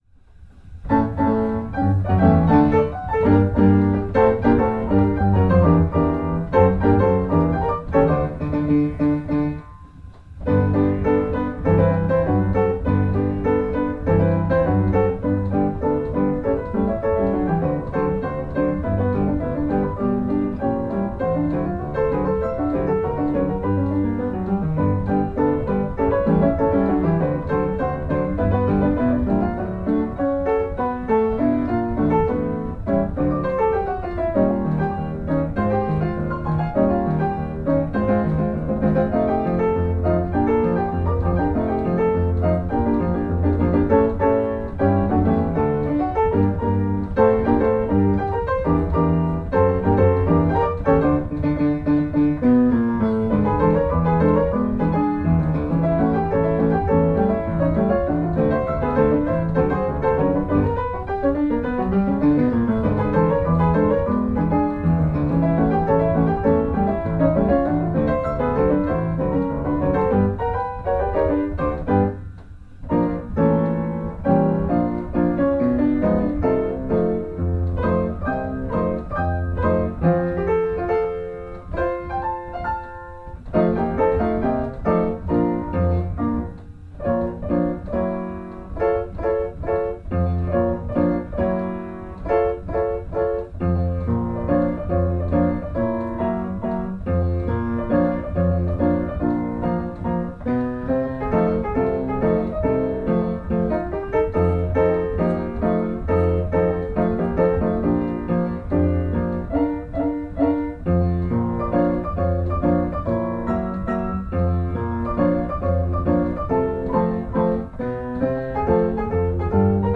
c fender funk funky loop minor piano rhodes sound effect free sound royalty free Music